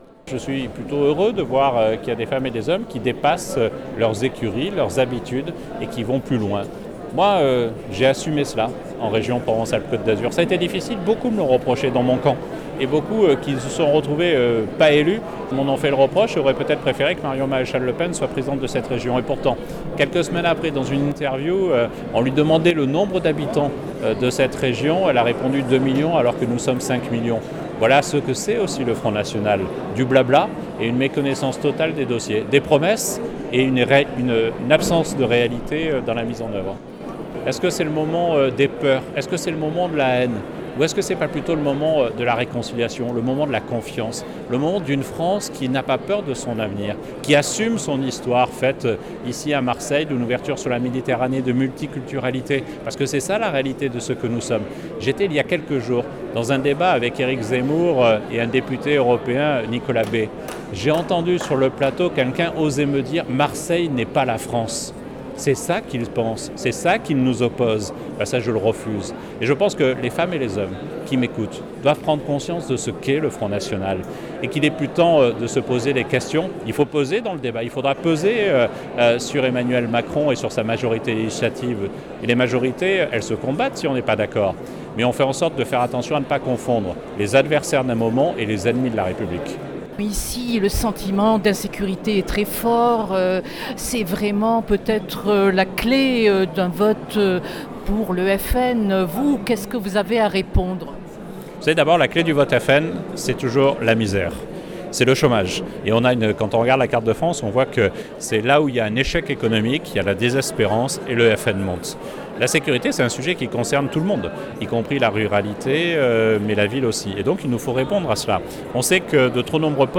En marge du meeting